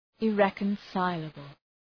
Shkrimi fonetik{ı’rekən,saıləbəl}